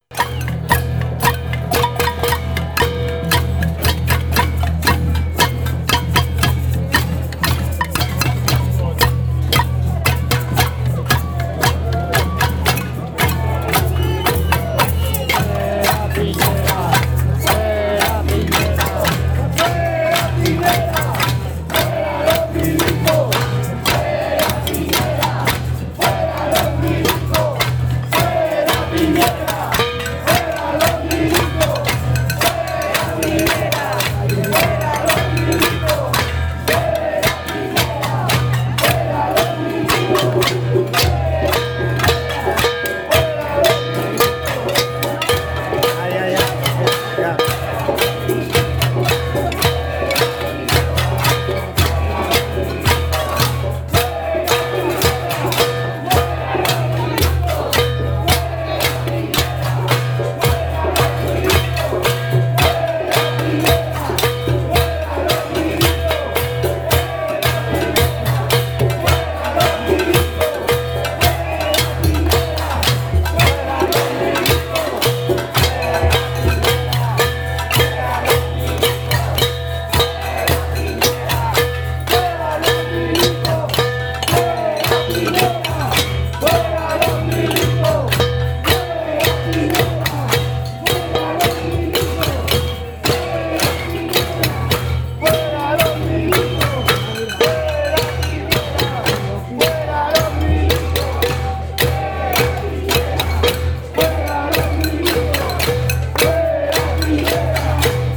o-Töne von Protest in Chile: